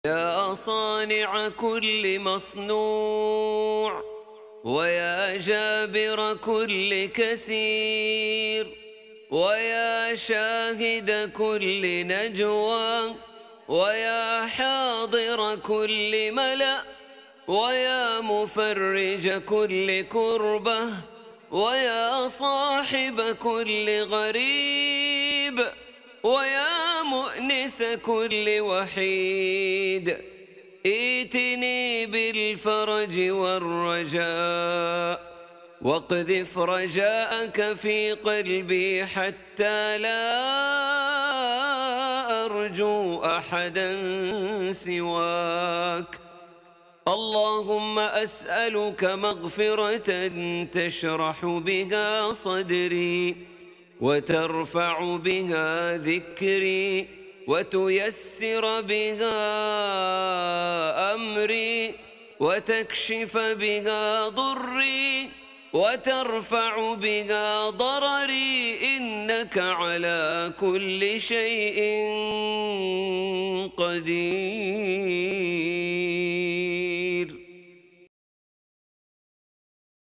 دعاء خاشع مليء بالأنس بالله والالتجاء إليه، يلجأ فيه الداعي إلى أسماء الله وصفاته طالباً تفريج الكروب وكشف الضر وتيسير الأمور. يعبر النص عن حالة من الرجاء الصادق والثقة المطلقة بقدرة الله تعالى على الاستجابة.